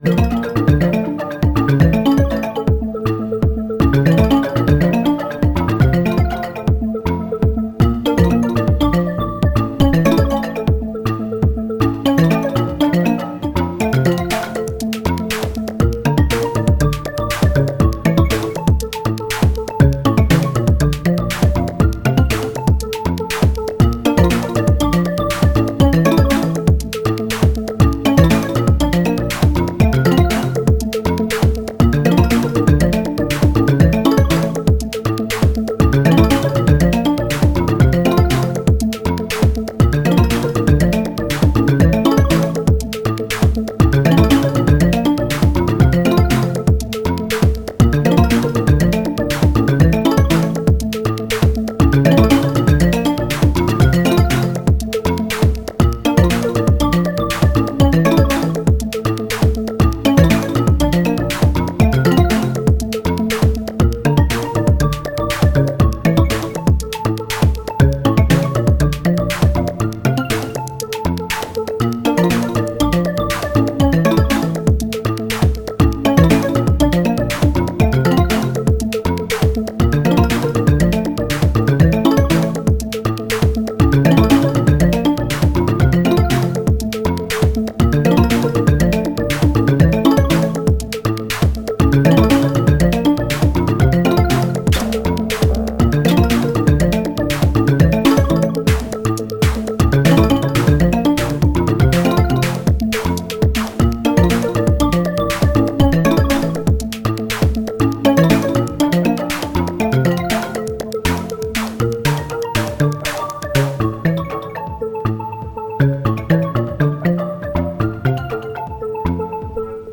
Volcas, DN into OT. Enya techno. 🤷